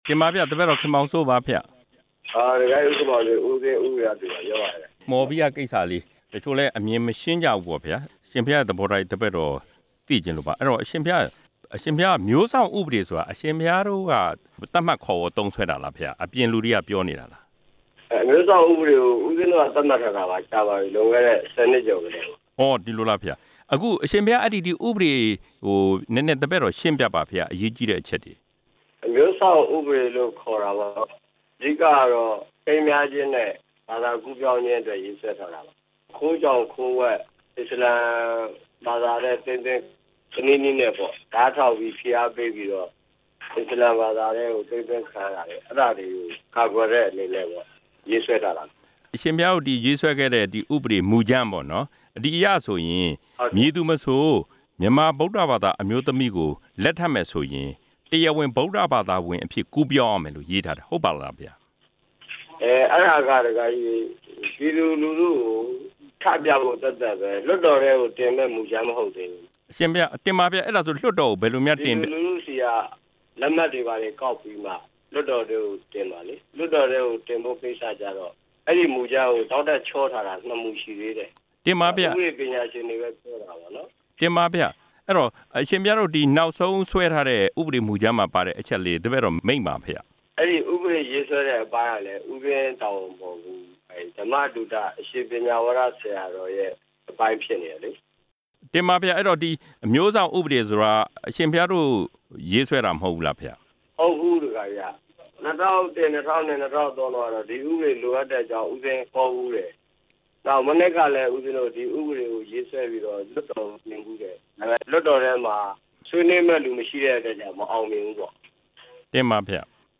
အရှင်ဝီရသူနဲ့ မိန့်ကြားချက်